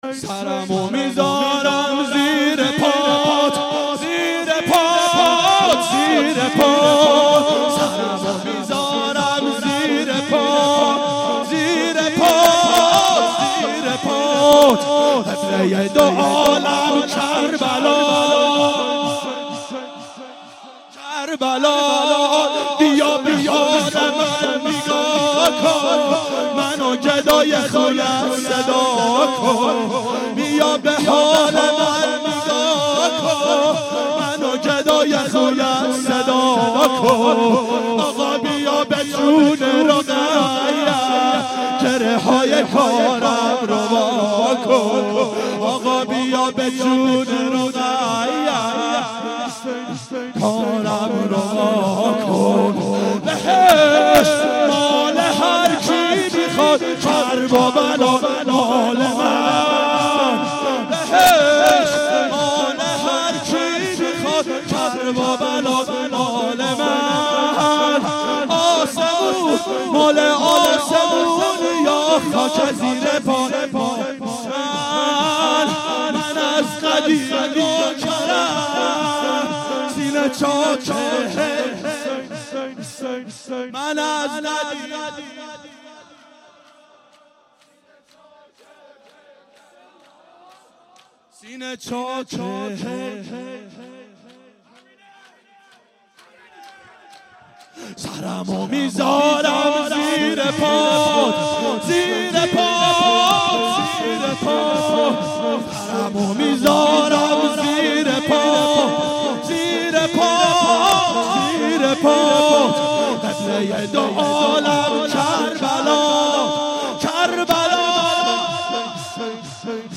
• دهه اول صفر سال 1392 هیئت شیفتگان حضرت رقیه سلام الله علیها